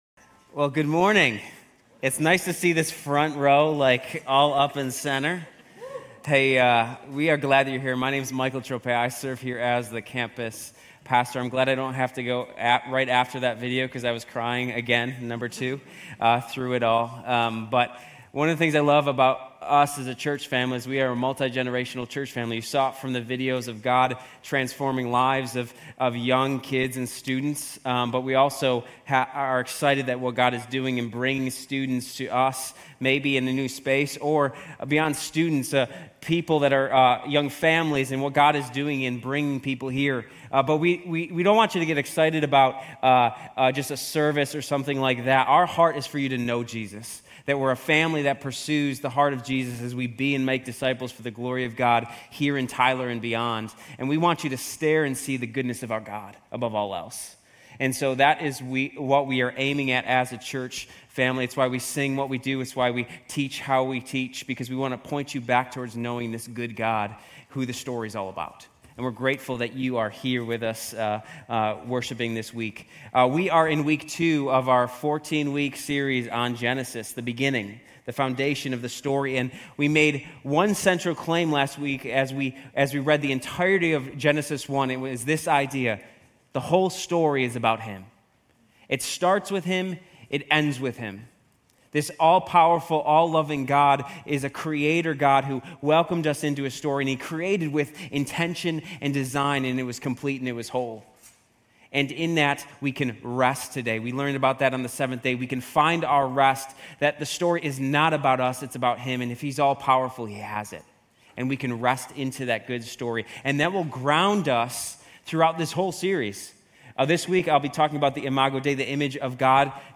Grace Community Church University Blvd Campus Sermons Genesis 1 - Image of God Aug 25 2024 | 00:30:55 1x 00:00 / 00:30:55 Subscribe Share RSS Feed Share Link Embed